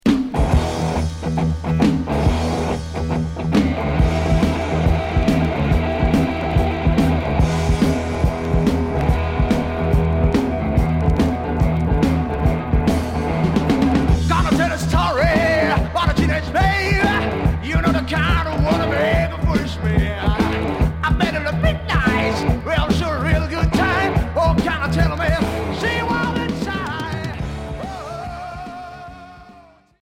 Heavy rock boogie Deuxième 45t retour à l'accueil